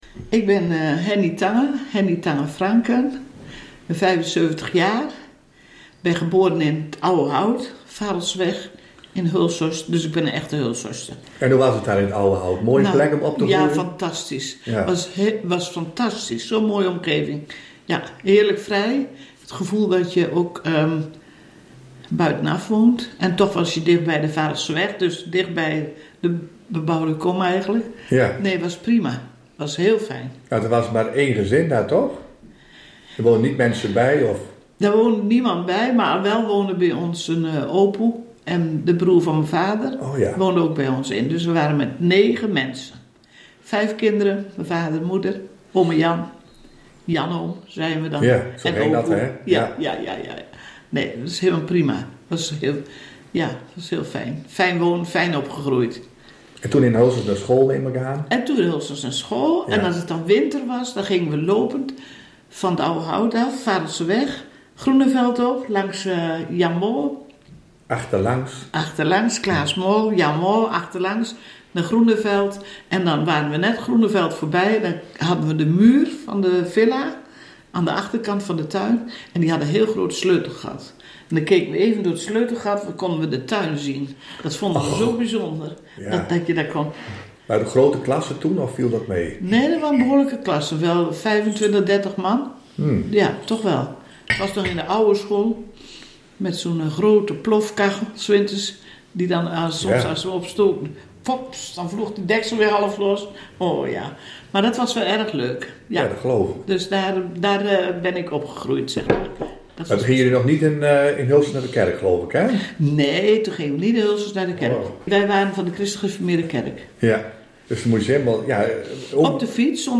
Hulshorsters aan het woord